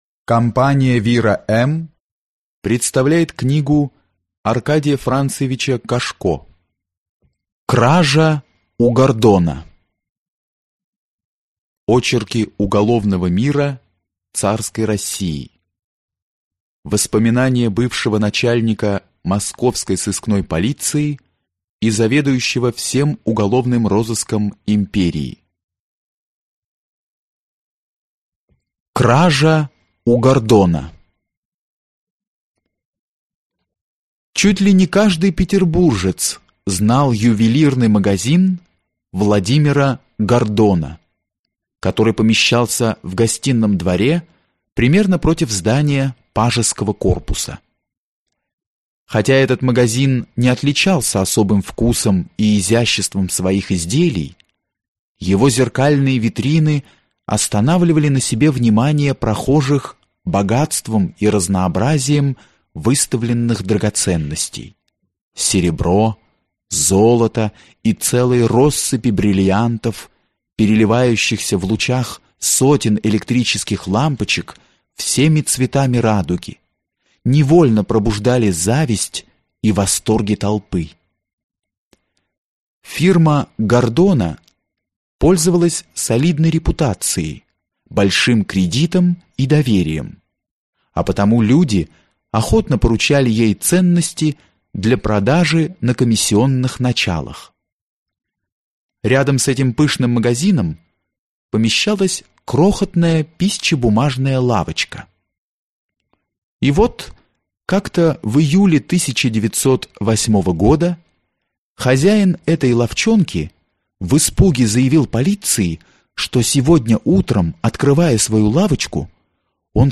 Аудиокнига Кража у Гордона | Библиотека аудиокниг